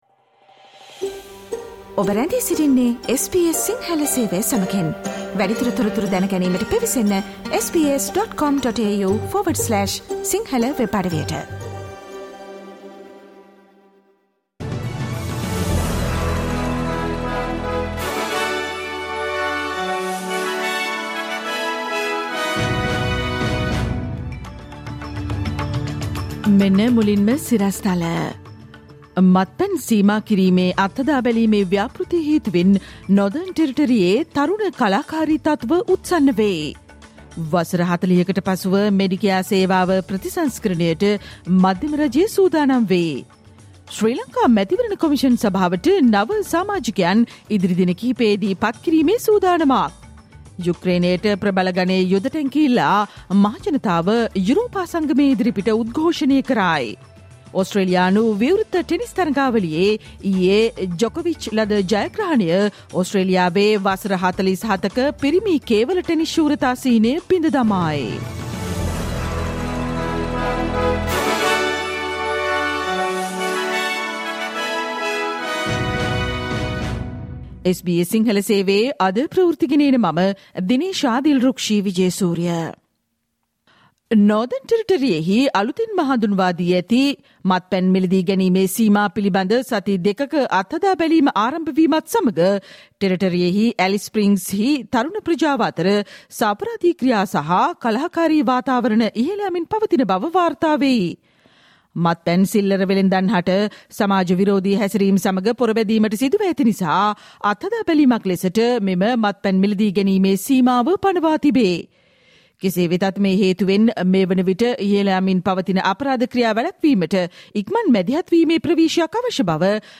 සවන්දෙන්න, අද - 2023 ජනවාරි 24 වන අඟහරුවාදා SBS ගුවන්විදුලියේ ප්‍රවෘත්ති ප්‍රකාශයට